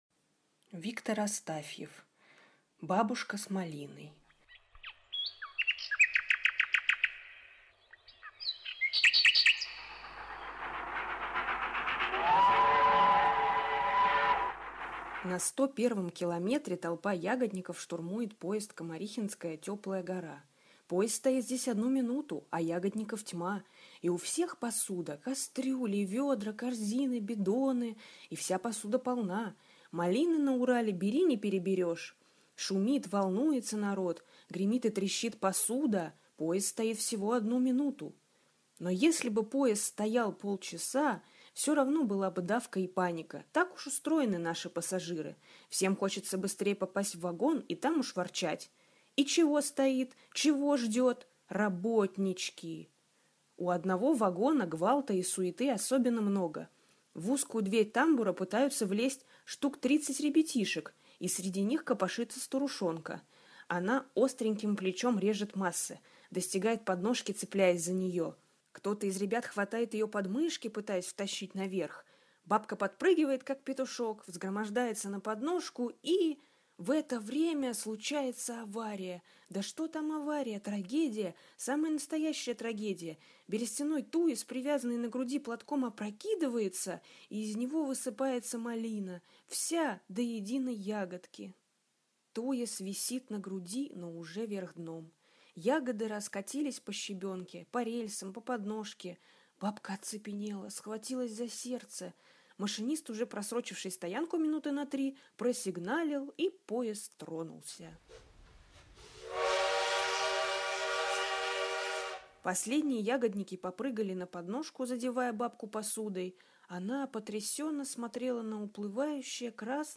Бабушка с малиной - аудио рассказ Астафьева В.П. Одна бабушка, забираясь на подножку поезда, случайно опрокинула туес с ягодами...